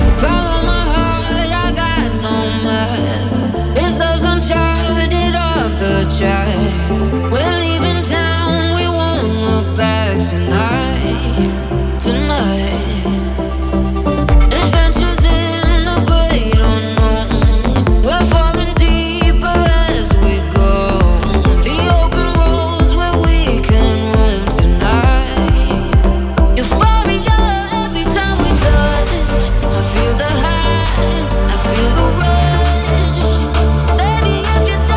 Жанр: Танцевальные / Транс